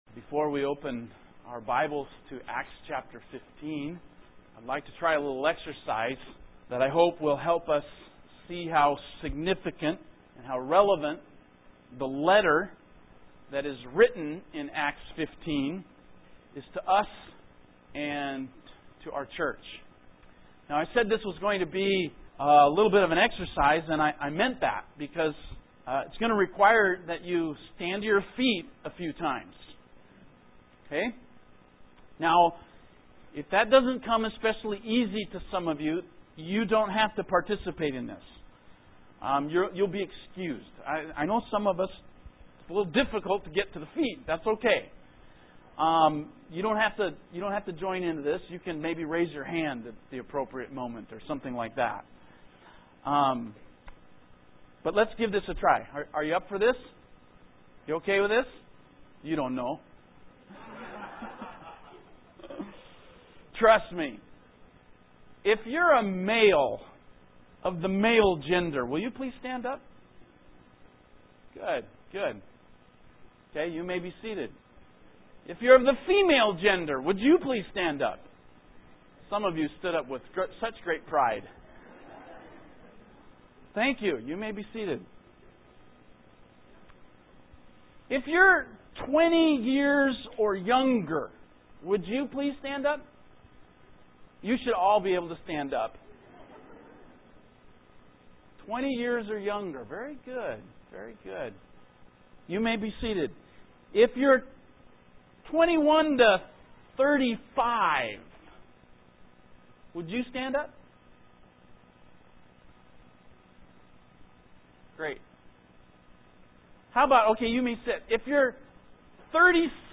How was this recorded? Evangelical Free Church in Washington State